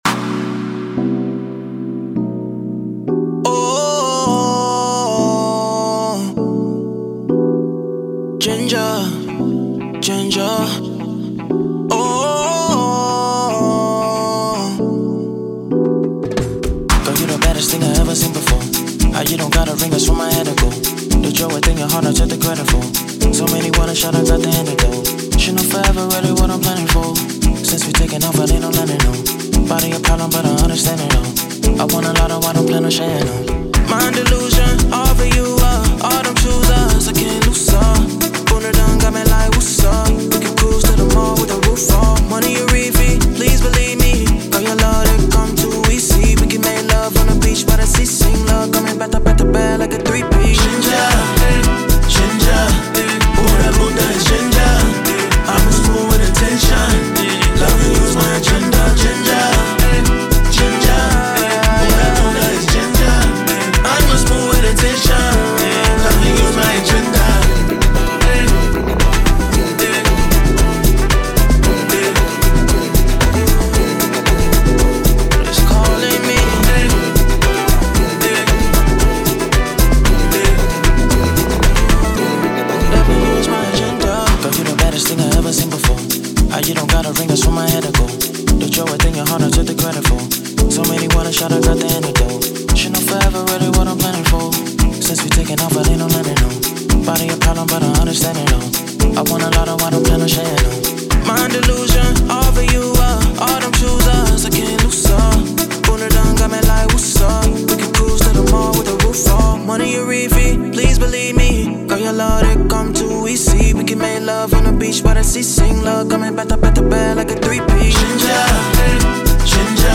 Afrobeat
Em
Club type afrobeat track about wanting and loving a woman